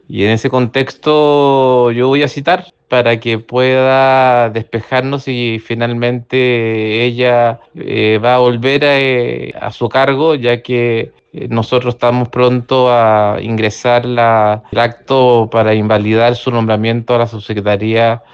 El diputado RN y presidente de la instancia, Andrés Celis, aseguró que persistirá en la citación, con el fin de precisar que pasará con el puesto que dejará vacante la exjefa comunal, ya que -dijo- están “pronto a ingresar el acto para invalidar su nombramiento a la subsecretaría”.